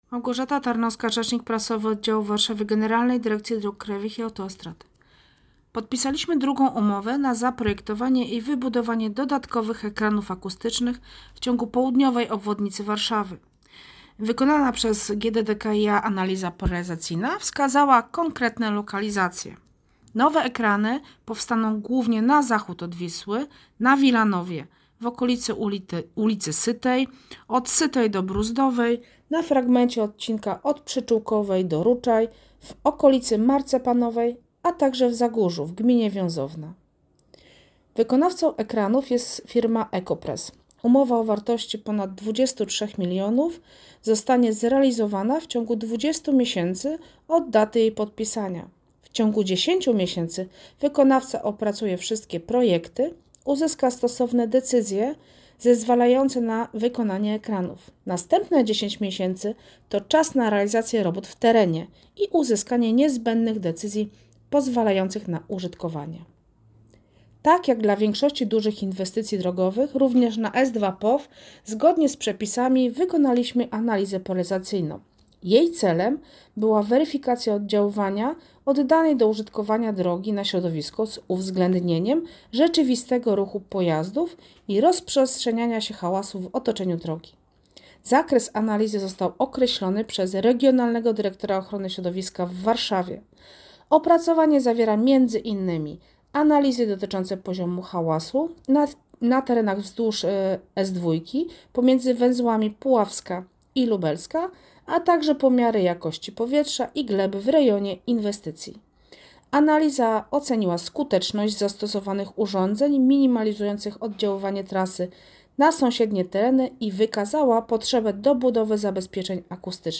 Jeszcze więcej ekranów akustycznych w ciągu S2 POW - wypowiedź rzecznika